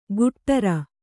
♪ guṭṭara